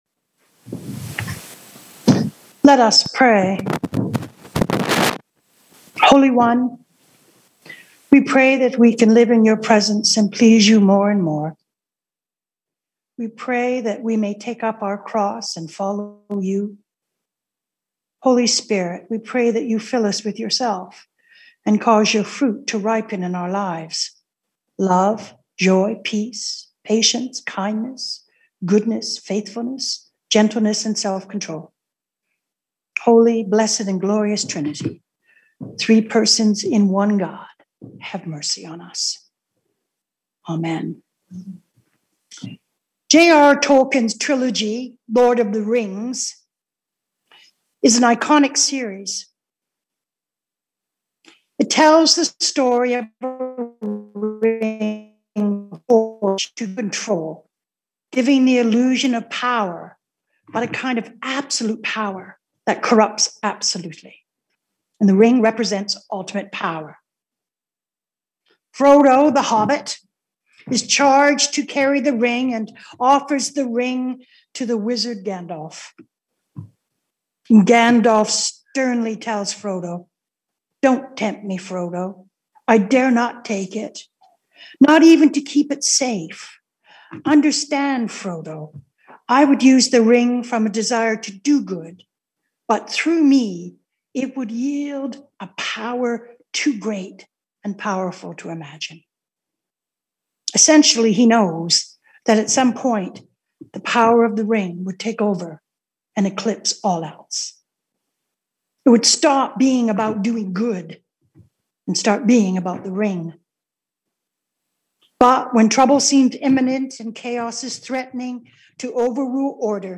Sermons | St. David and St. Paul Anglican Church